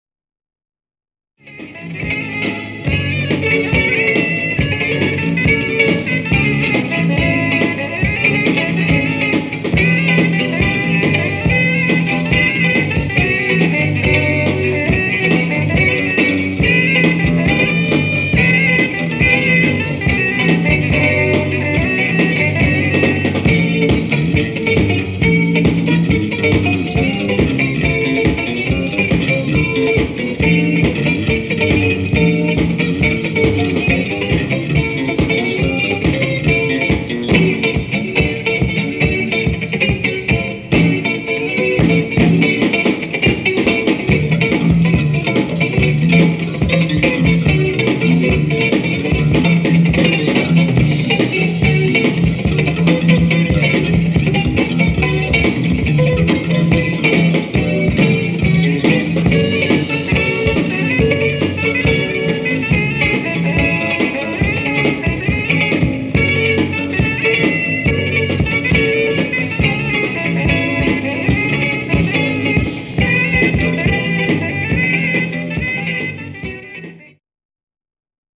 Tracks were live recordings on cassette from several gigs.